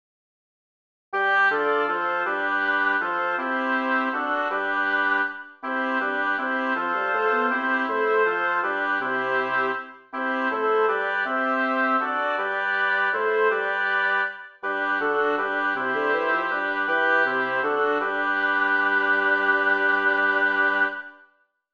Title: Die Sonne wird mit ihem Schein b Composer: Melchior Vulpius Lyricist: Michael Weiße Number of voices: 4vv Voicing: SSAT Genre: Sacred, Chorale
Language: German Instruments: A cappella